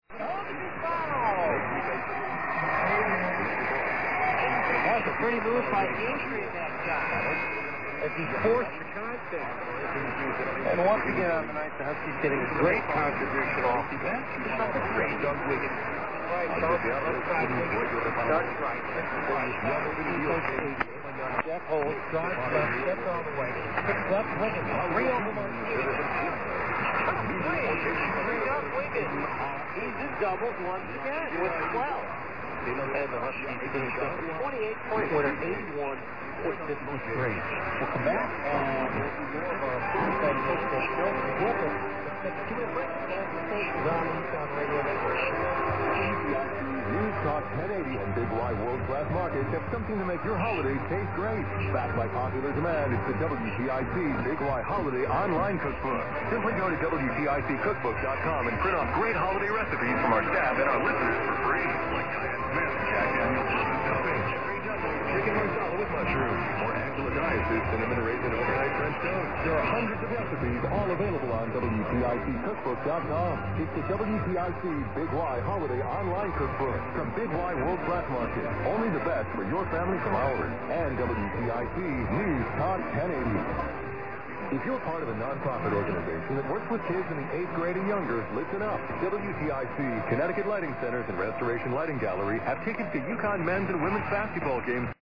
> The recent semi-auroral conditions have pretty much killed TA signals and
> 1080 NICARAGUA R. 15 de Septiembre, Managua DEC 7 0152 - Fair under
> semi-nulled WTIC with "Radio Quince de Septiembre" ID, "mil ochenta
> Nicaraguan national anthem.